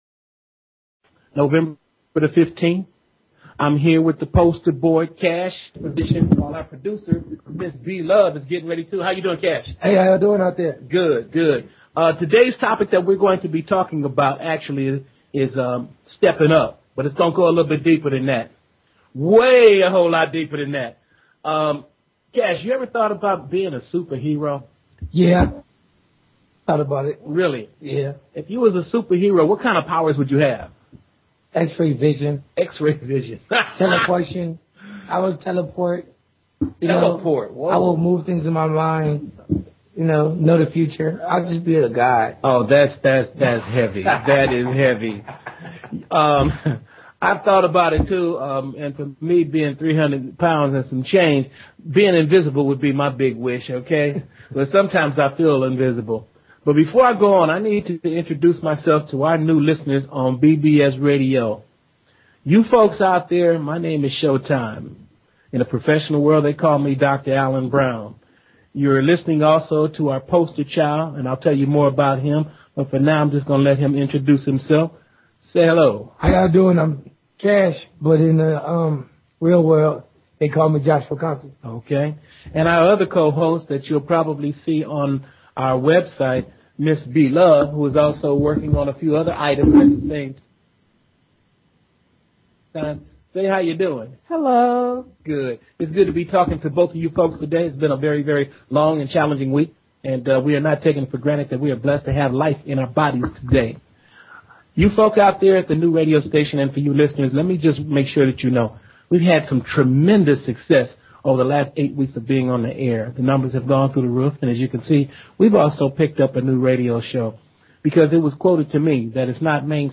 Talk Show Episode, Audio Podcast, Grassroots_Talks and Courtesy of BBS Radio on , show guests , about , categorized as